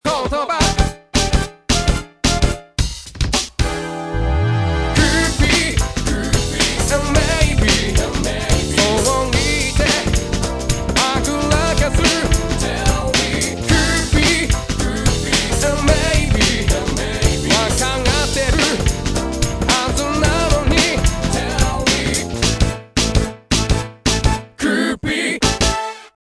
音量のバランス、声の音色等、かなりすっきりしていませんか？
本ホームページではモノラルで音質も下がっていますので